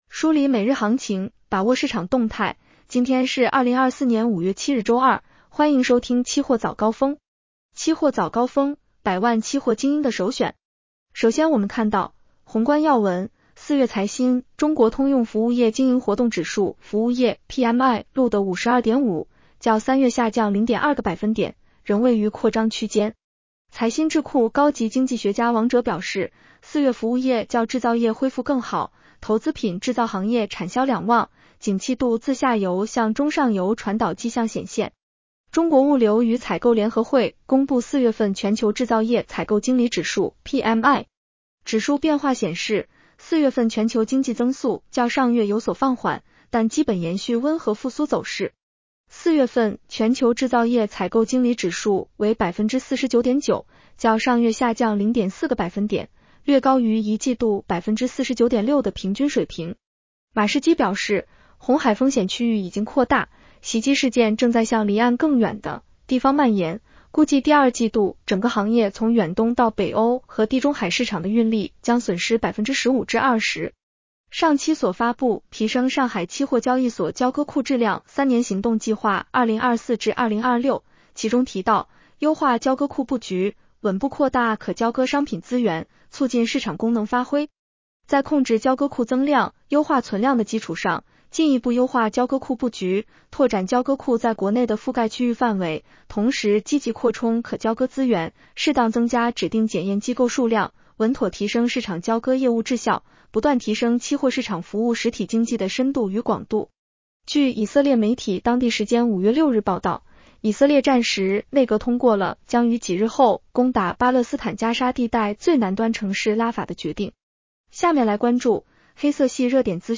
期货早高峰-音频版 女声普通话版 下载mp3 宏观要闻 1. 4月财新中国通用服务业经营活动指数（服务业PMI）录得52.5，较3月下降0.2个百分点，仍位于扩张区间。